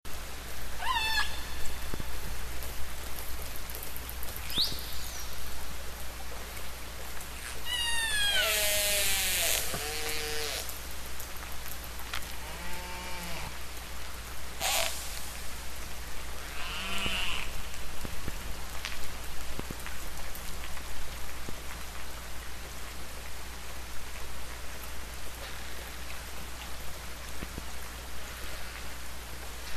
Звуки издаваемые косаткой